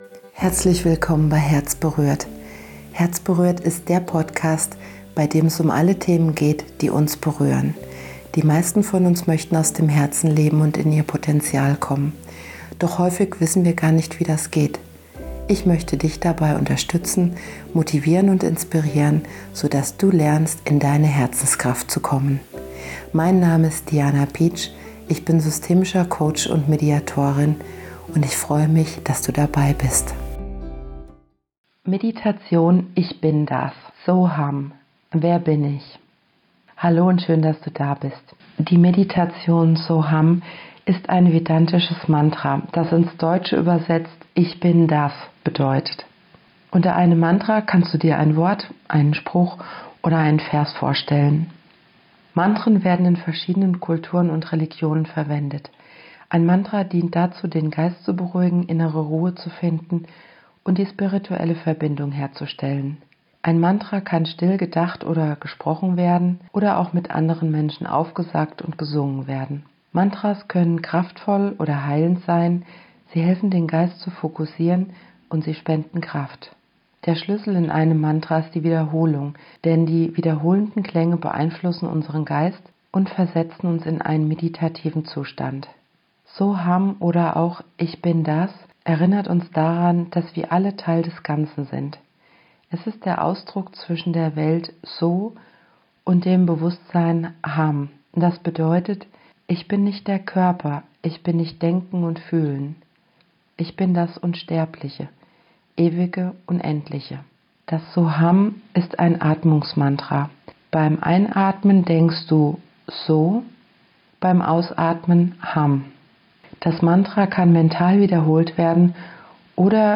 Folge 28: Meditation: ICH BIN DAS | So Ham – wer bin ich? (963 Hz